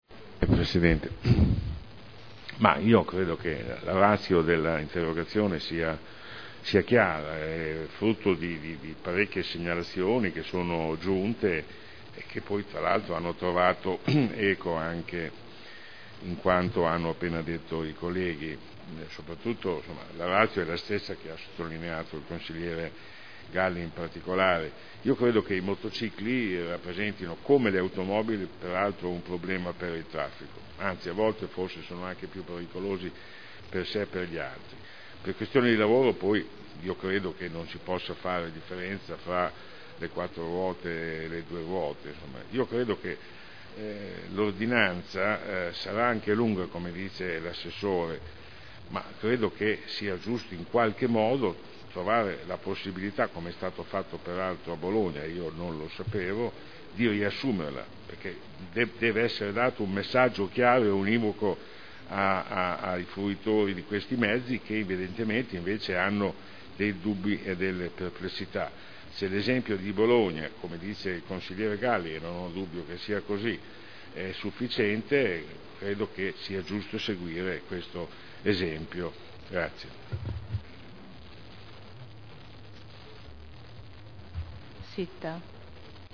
Seduta del 09/11/2009. Confusione nell'accesso dei motocicli alla zona ZTL